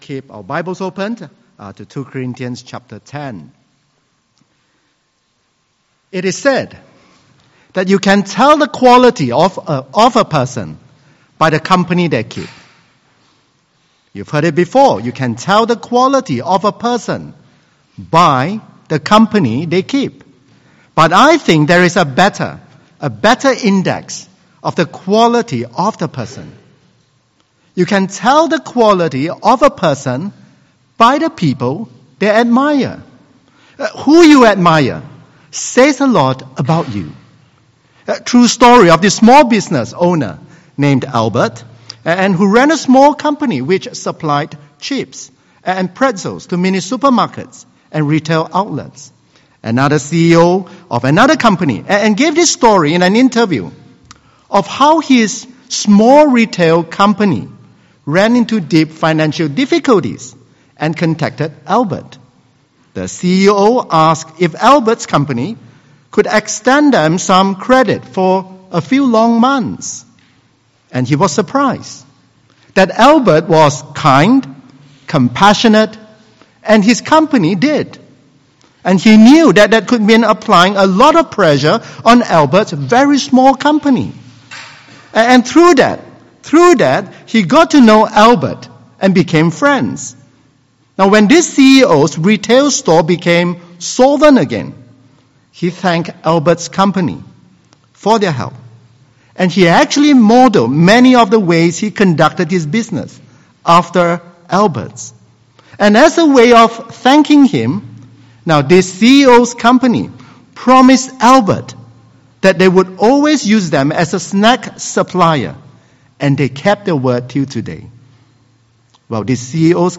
The Cross and The Crown Passage: 2 Corinthians 10:1-18 Service Type: 11 AM « What is a Protestant?